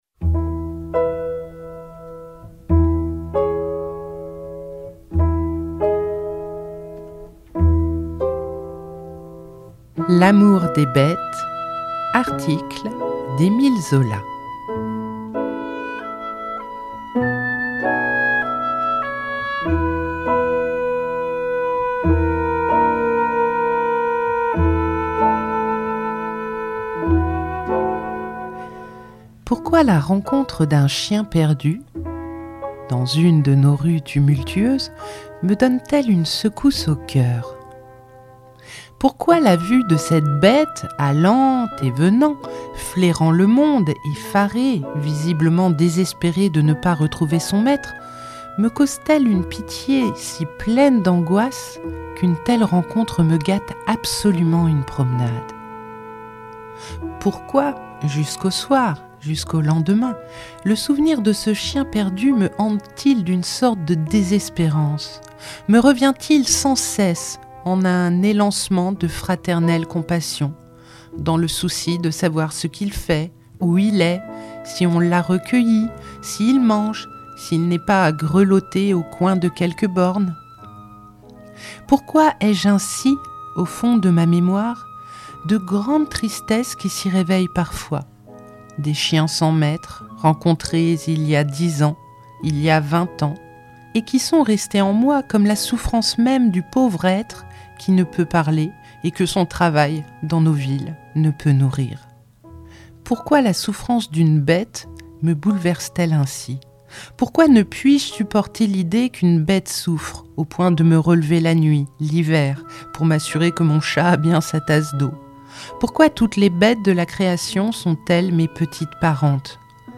Dans ce radiobook, cet article est suivi d’une lettre.